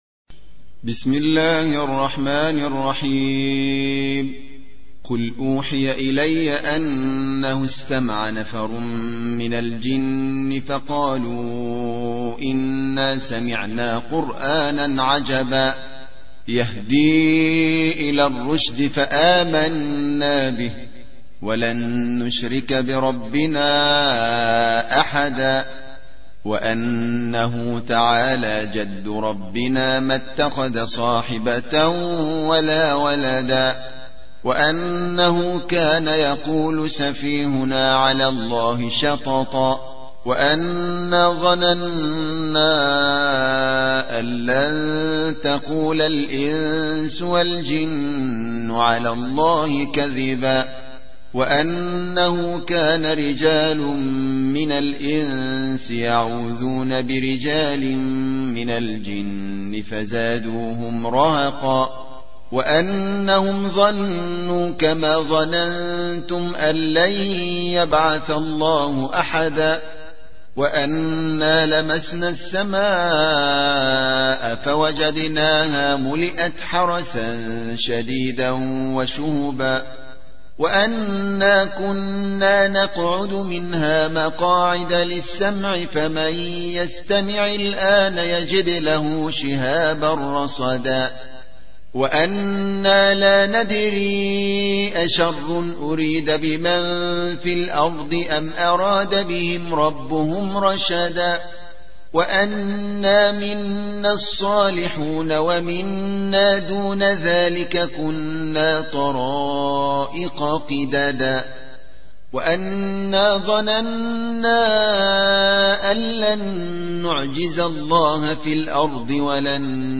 72. سورة الجن / القارئ